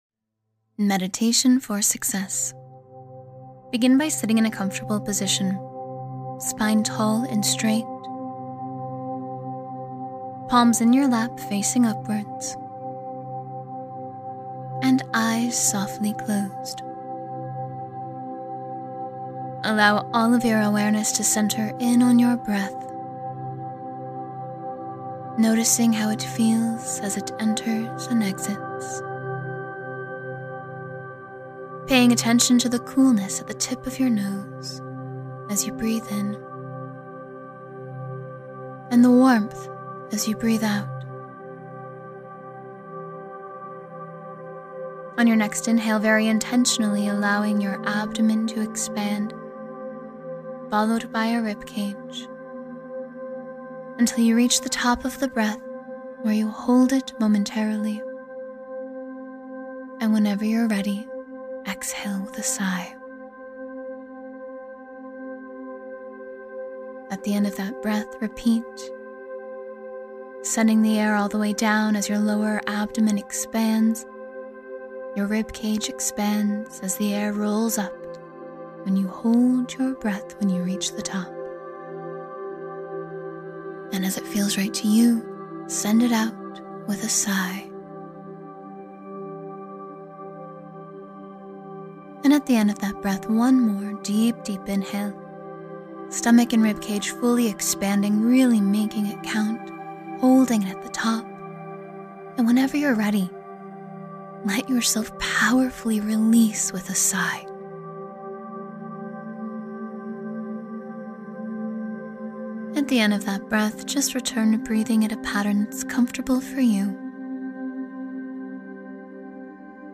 Manifest Success and Confidence — Guided Meditation to Boost Your Day